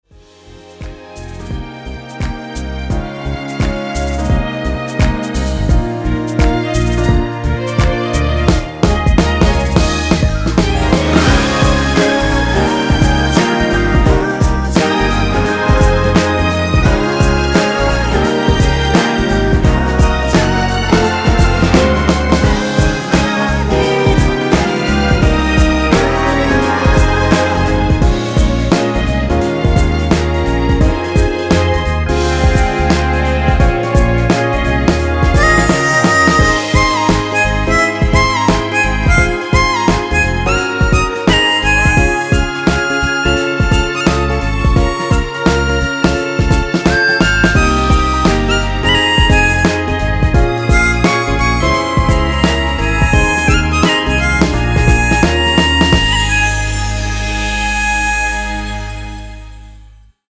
엔딩이 페이드 아웃이라서 노래하기 편하게 엔딩을 만들어 놓았으니 미리듣기 확인하여주세요!
원키 코러스 포함된 MR입니다.
Ab
앞부분30초, 뒷부분30초씩 편집해서 올려 드리고 있습니다.